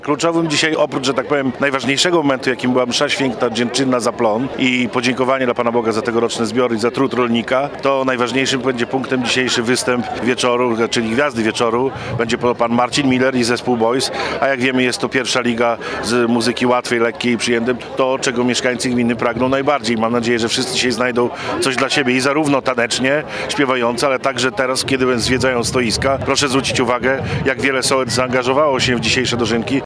– Obchody święta rolników rozpoczęliśmy mszą dziękczynną – mówi wójt gminy Ełk, Tomasz Osewski.